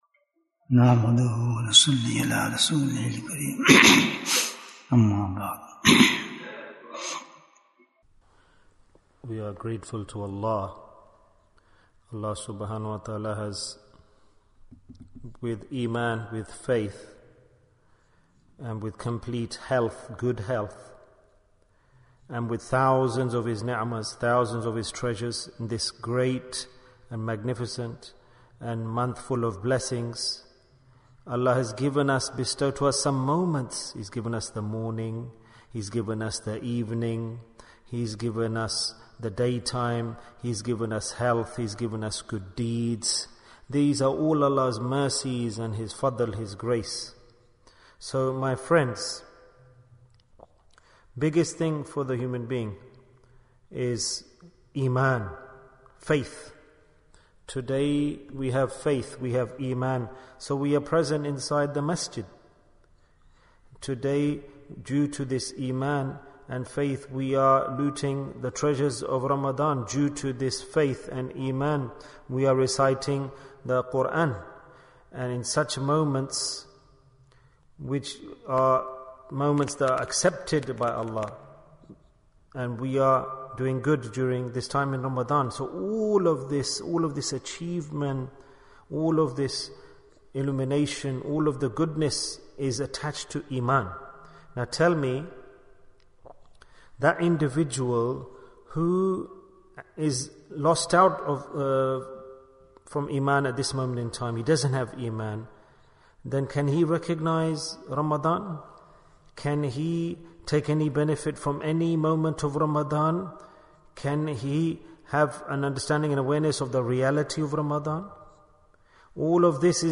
To Have Iman is One Thing & Another to Depart With Iman Bayan, 41 minutes6th April, 2023